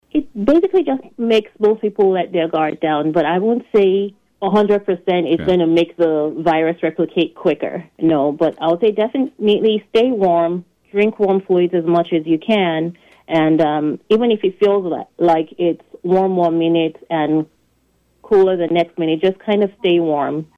As we get deeper into the holiday and new year season, we are also quickly approaching the peak flu season, according to an illness trend update by Lyon County Health Officer Dr. Ladun Oyenuga on KVOE’s Morning Show Monday.